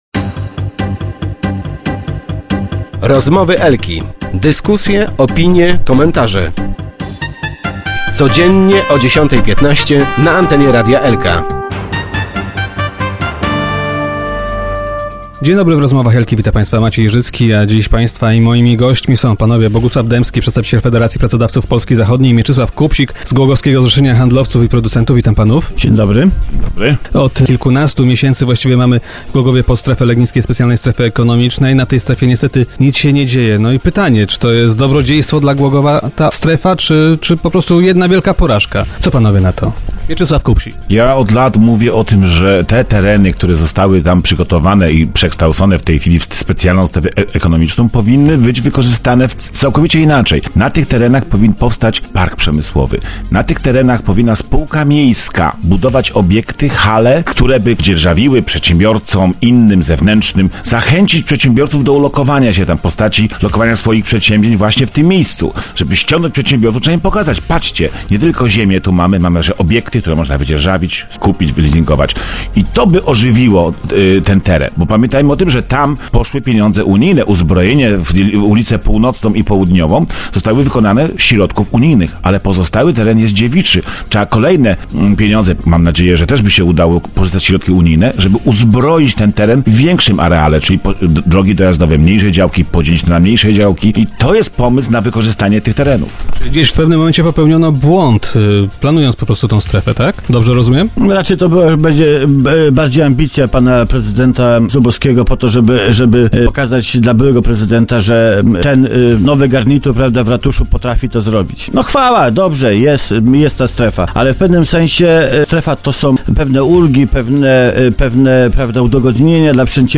Start arrow Rozmowy Elki arrow Przedsiębiorcy: Chcemy prezydenta biznesmena